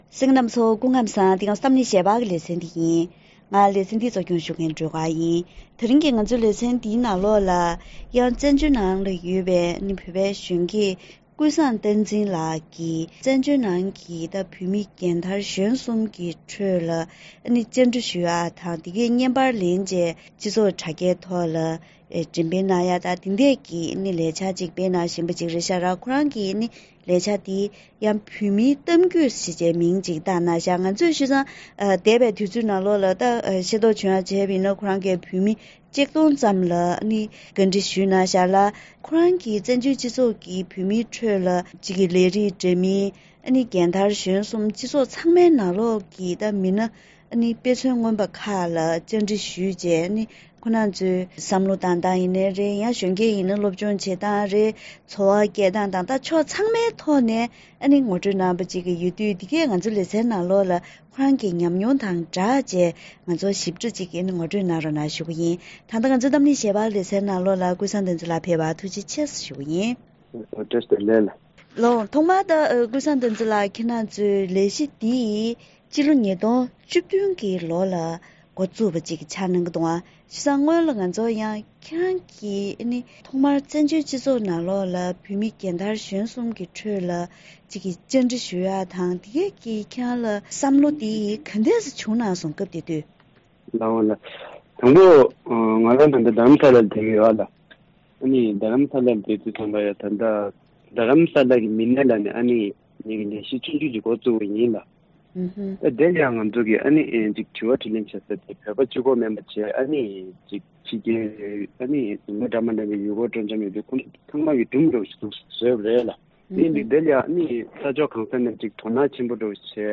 ཞིབ་ཕྲའི་གནས་ཚུལ་འབྲེལ་ཡོད་དང་ལྷན་དུ་བཀའ་མོལ་ཞུས་པ་ཞིག་གསན་རོགས་གནང་།